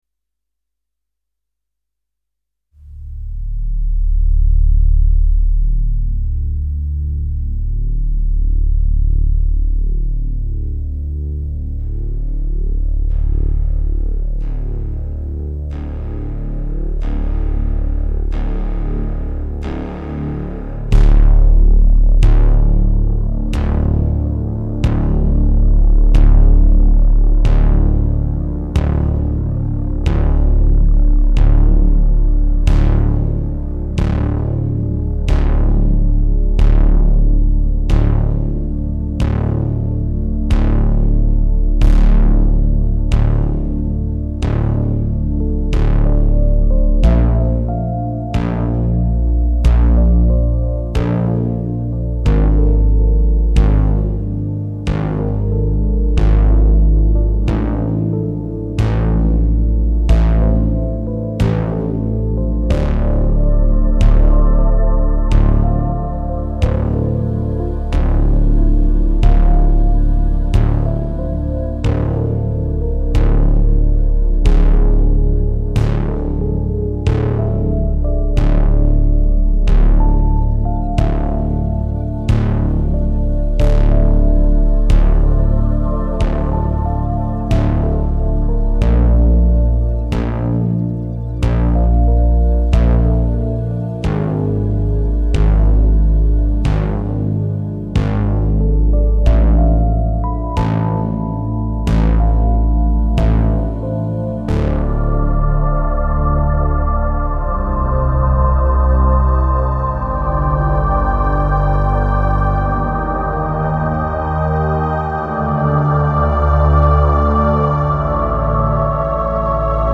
...a gently troubled sea...